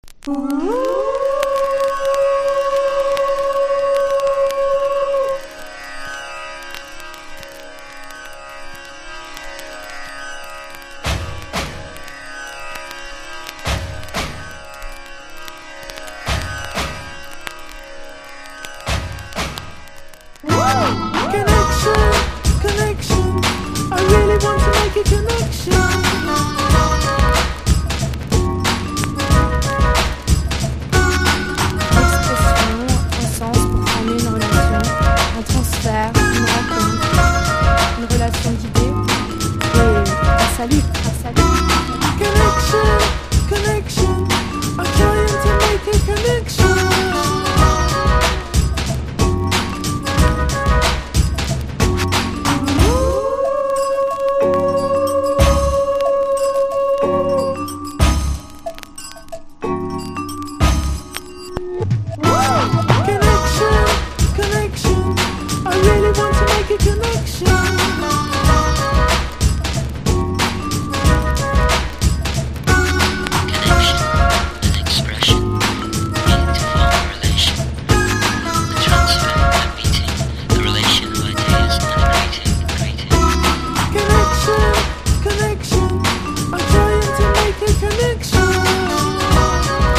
1. 90'S ROCK >
NEO ACOUSTIC / GUITAR POP